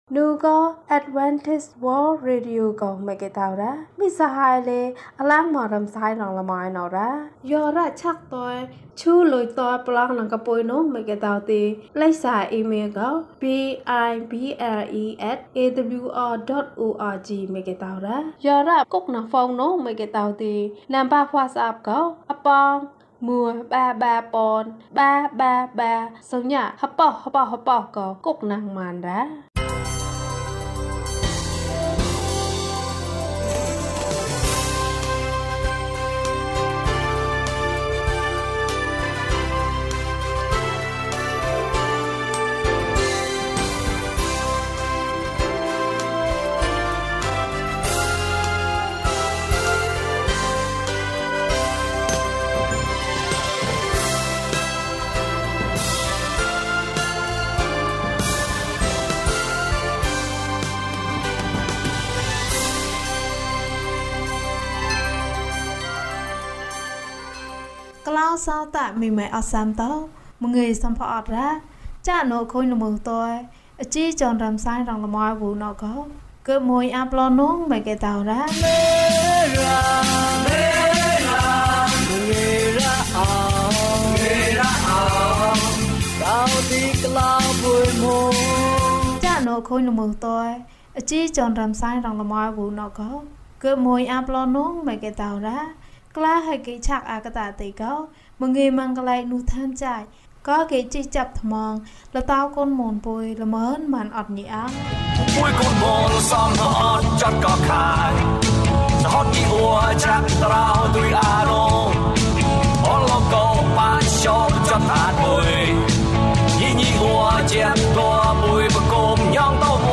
စေတနာဖြင့် ကူညီပေးခြင်း။ ကျန်းမာခြင်းအကြောင်းအရာ။ ဓမ္မသီချင်း။ တရားဒေသနာ။